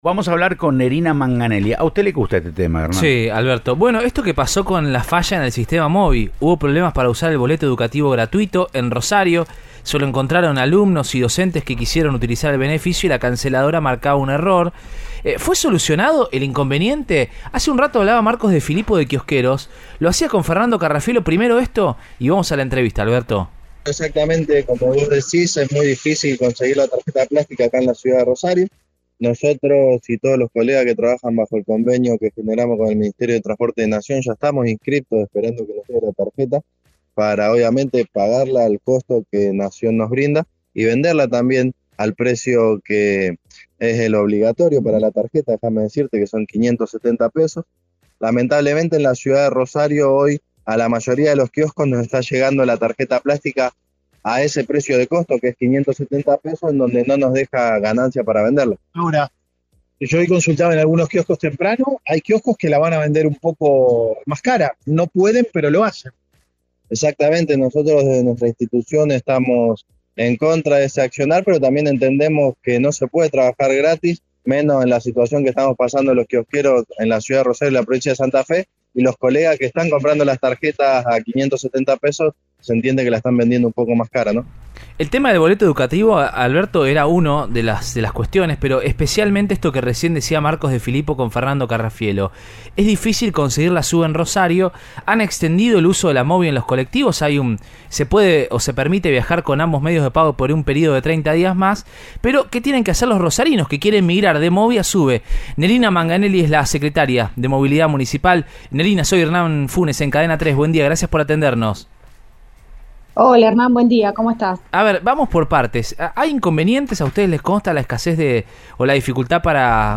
Nerina Manganelli, secretaria de Movilidad de la Municipalidad de Rosario, habló en Siempre Juntos y explicó: “Tenemos puntos oficiales para vender y recargar la tarjeta. El municipio garantizó que iba a tener tarjetas en los puntos de registración, con lo cual no existe la problemática. Ahora bien, en los kioscos, la responsabilidad es del Ministerio de Transporte de la Nación. Tanto la de las cargas como la de los plásticos”.